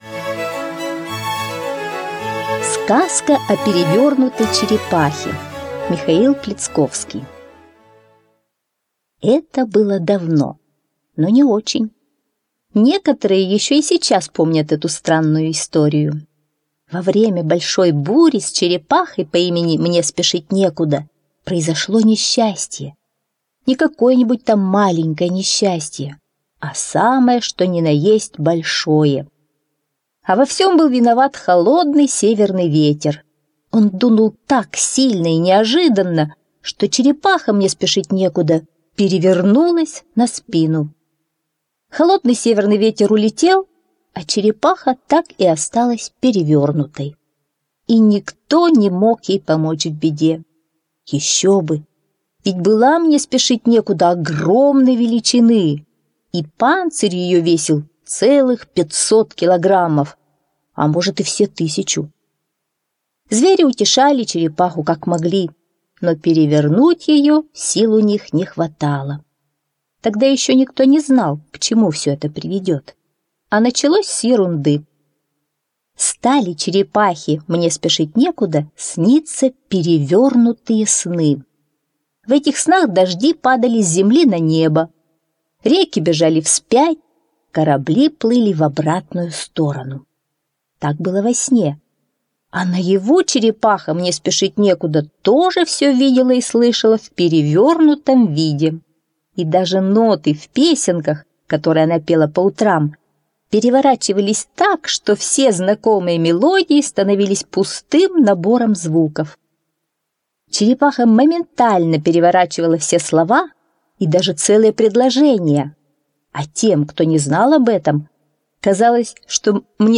Сказка о перевернутой черепахе - аудиосказка Пляцковского